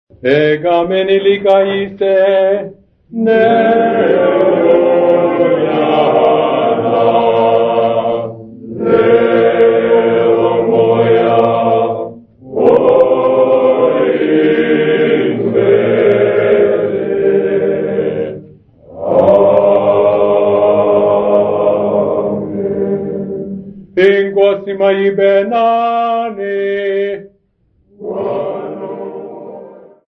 Zingisa Seminary Congregation
Folk music
Sacred music
Field recordings
Africa South Africa Umtata sa
Unaccompanied Catholic prayer and response.
96000Hz 24Bit Stereo